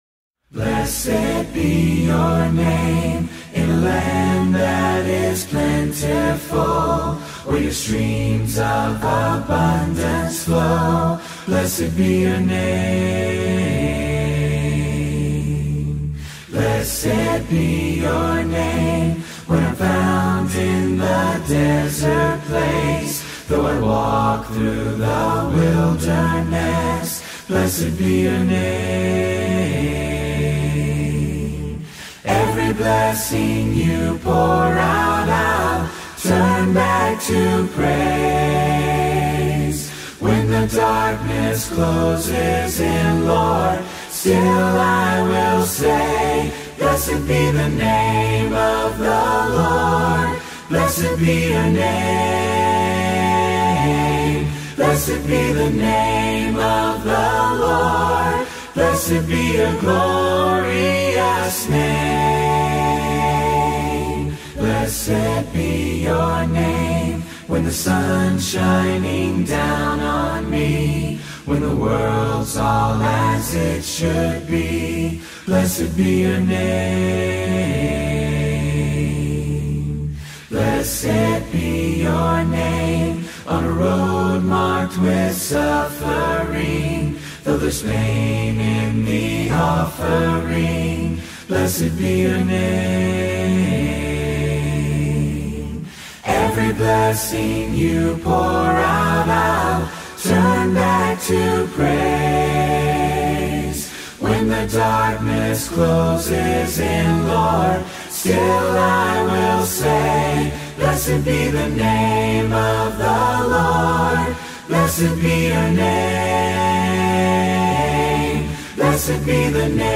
Blessed-Be-Your-Name-acapella.mp3